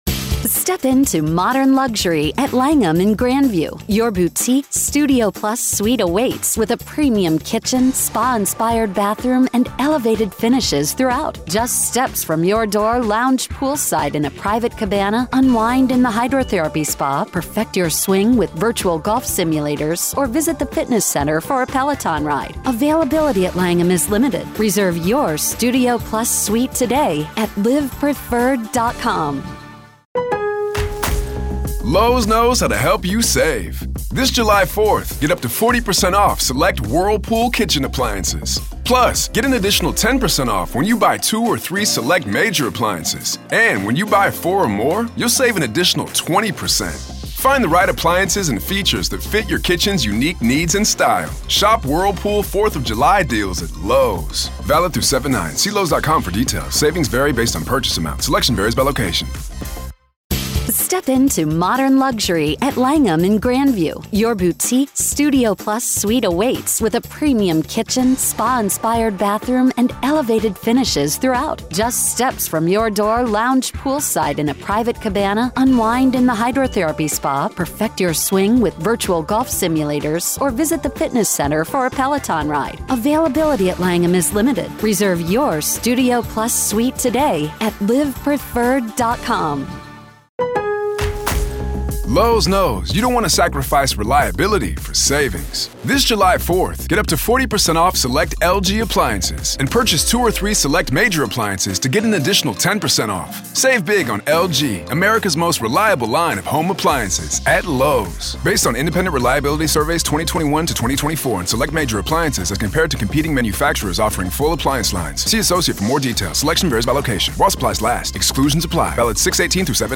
May 17, 2023, 11:00 AM Facebook Twitter Headliner Embed Embed Code See more options In this deeply engaging episode, we delve into the unsettling case of Lori Vallow Daybell, whose actions have provoked widespread shock and perplexity. Our listeners call in, sharing their perspectives and emotions, as we explore the circumstances that led to such a devastating outcome.